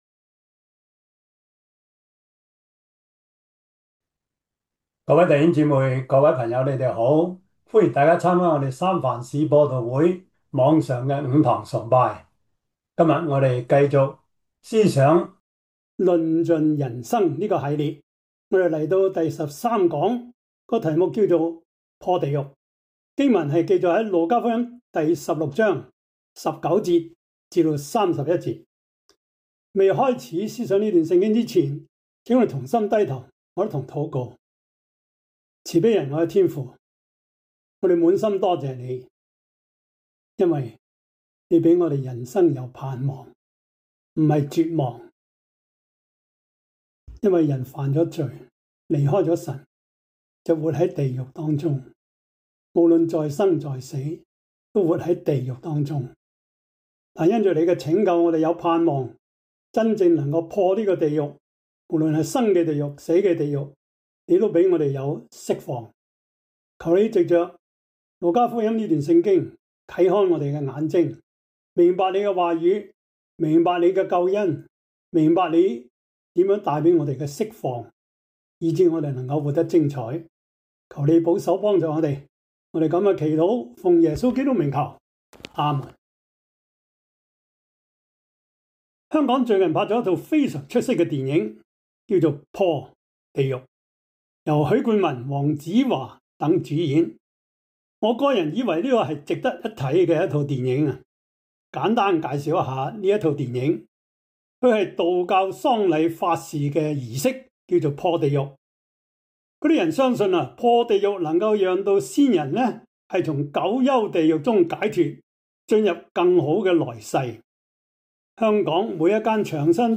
路加福音16:19-31 Service Type: 主日崇拜 路加福音16:19-31 Chinese Union Version